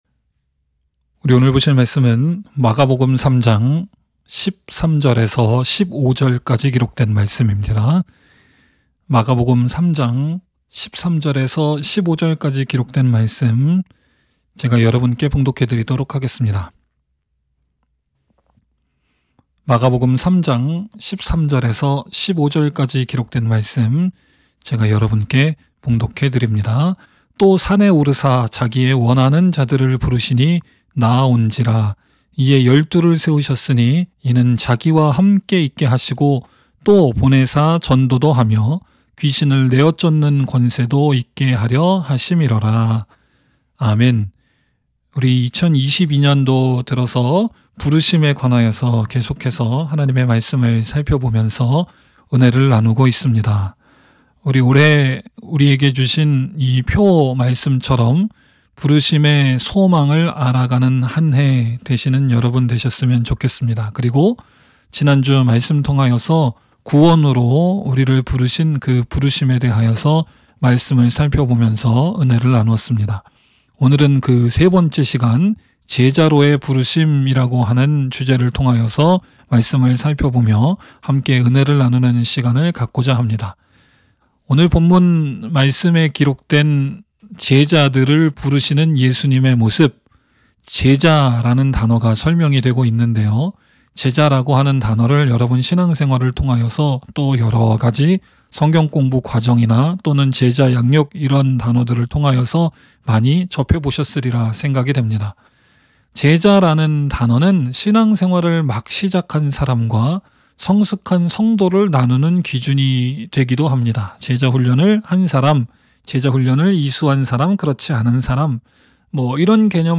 by admin-new | Jan 17, 2022 | 설교 | 0 comments